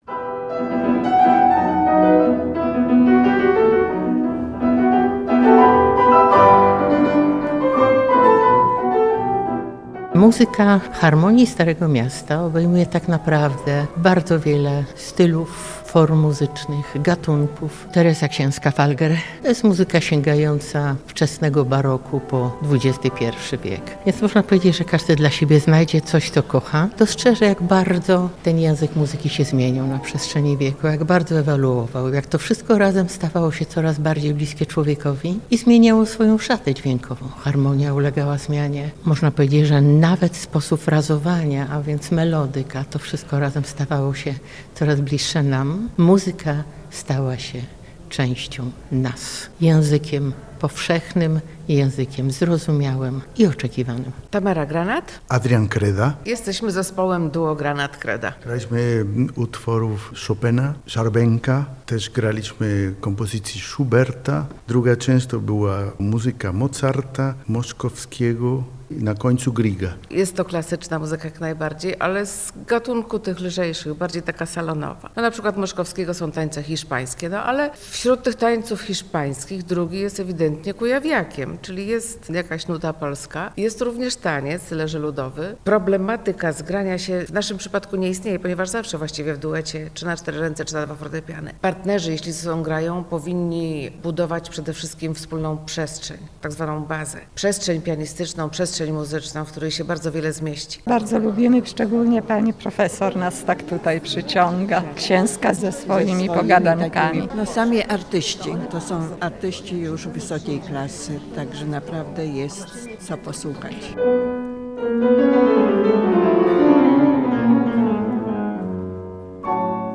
Z tej okazji, w Trybunale Koronnym odbył się koncert